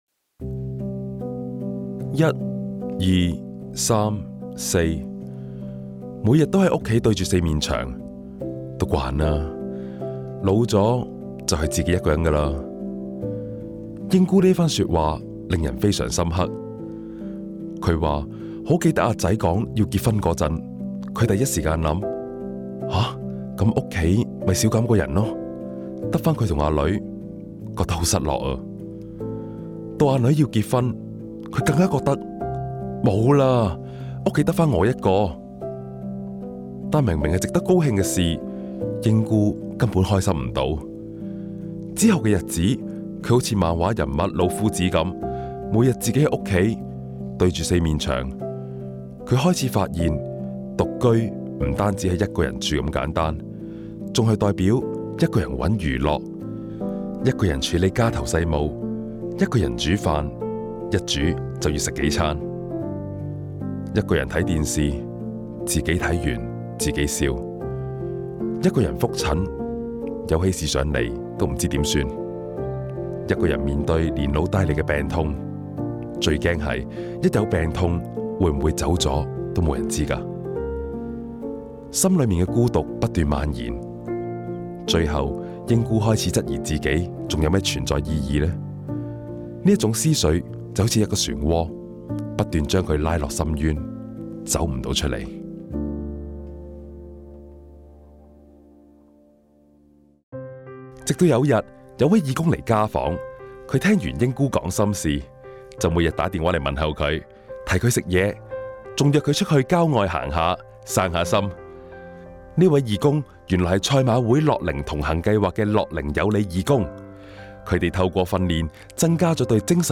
真人演繹有聲故事